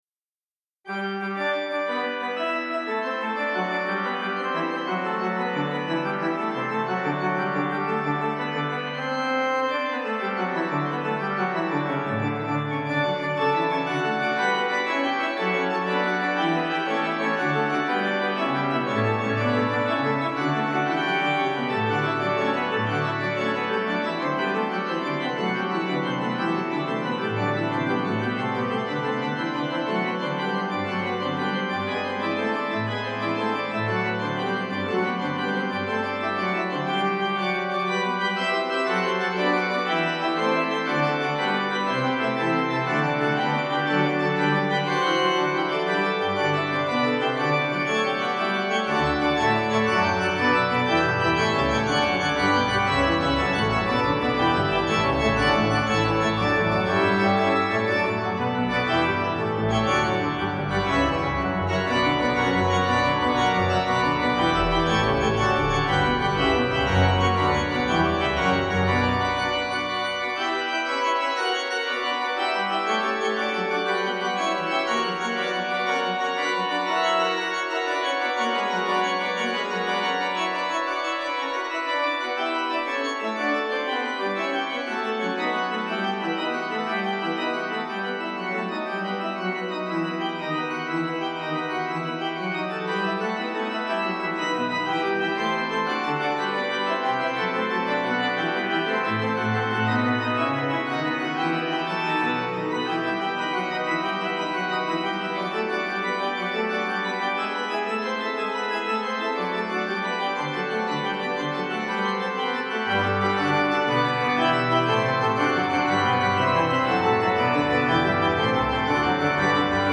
Organ
Easy Listening   D